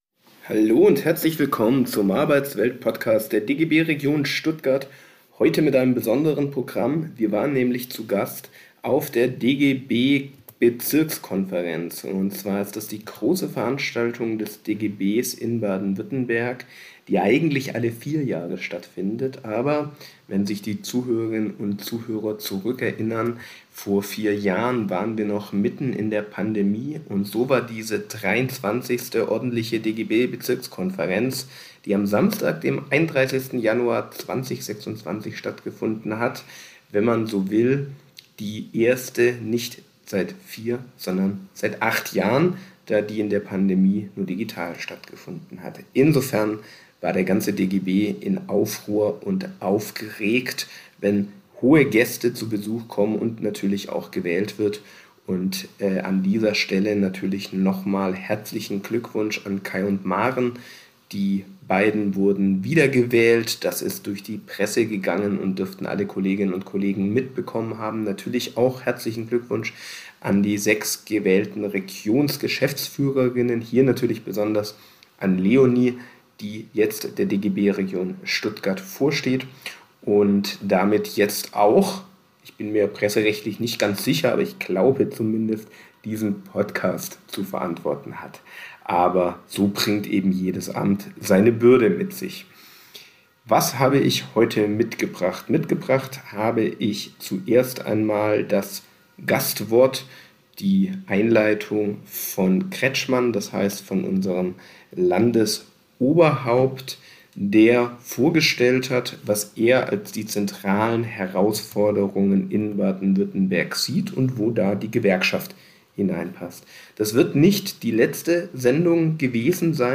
In dieser Funktion war er bei der 23. ordentlichen Bezirkskonferenz des DGB-BaWü eingeladen für ein Grußwort. Die 40 minütige Rede von Kretschmann dokumentieren wir hier im Arbeitswelt Podcast.